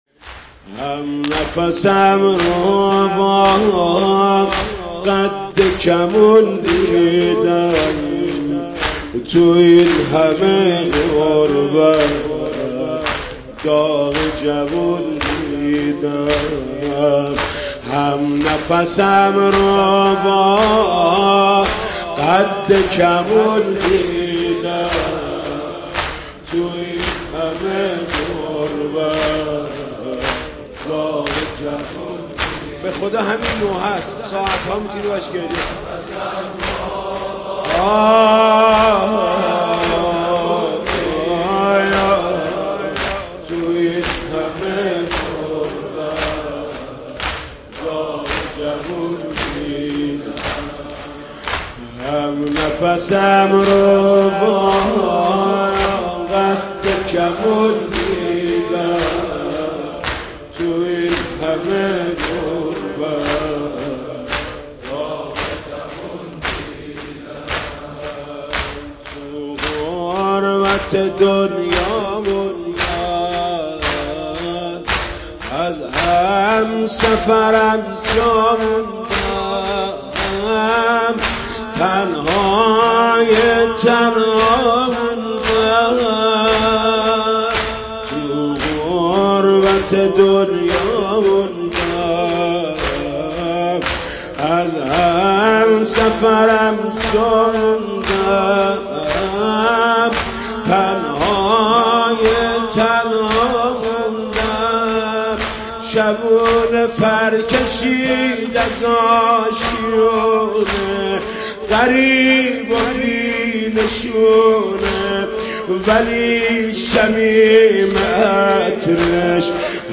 مداحی صوتی
نوحه جدید